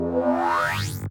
loader_charge.ogg